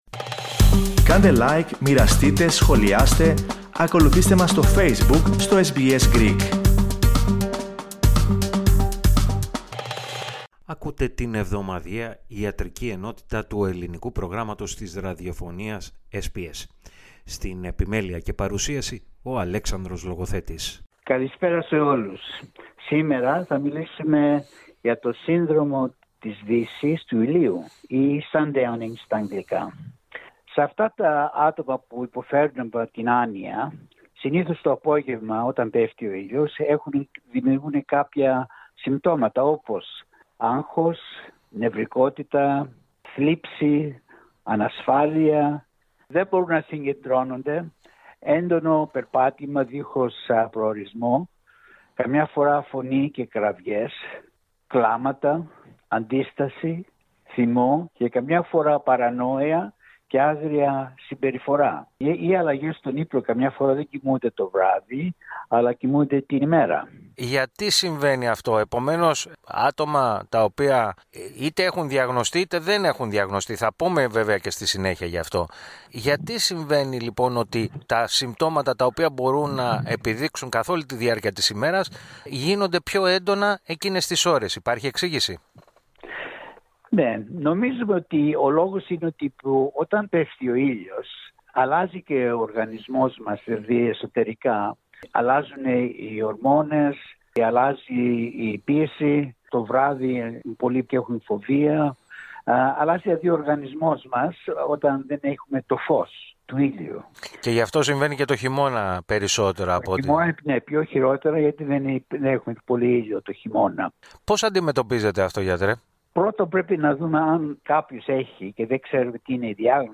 Ο γενικός γιατρός